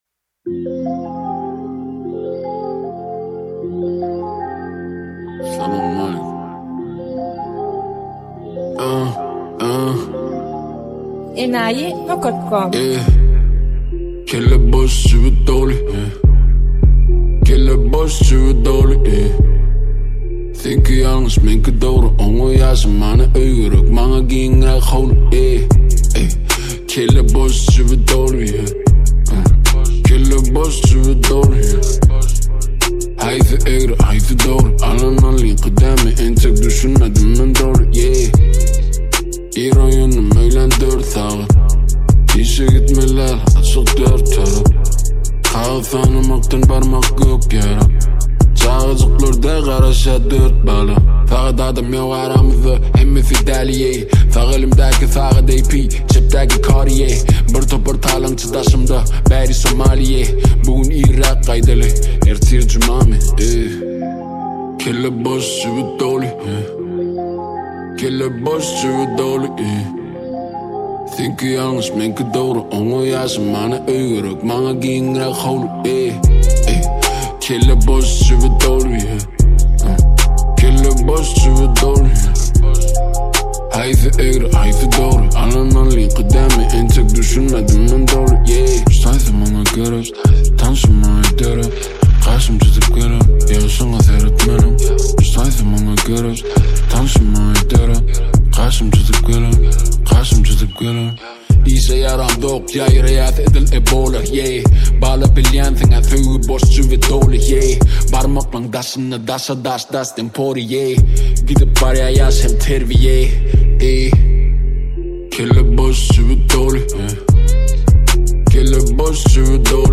Туркменские песни